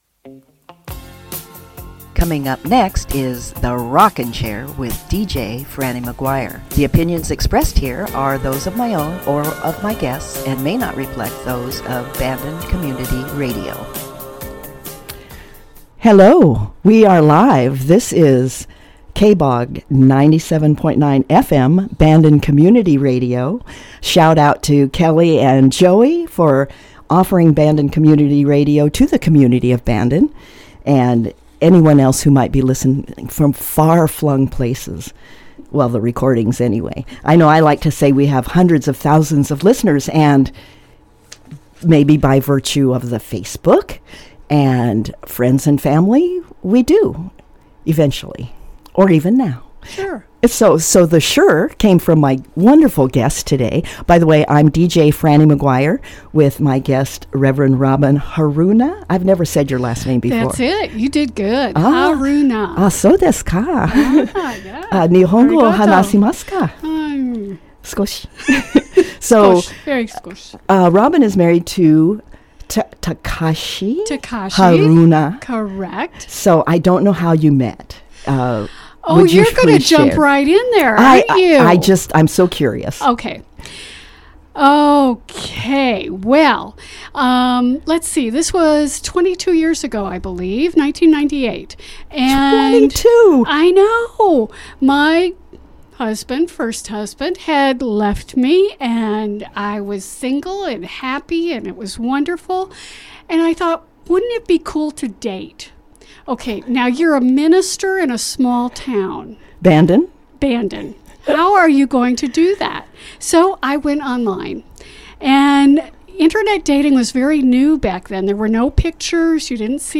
Some Sundays, a local Bandonite will co-host The Rockin’ Chair and choose the playlist.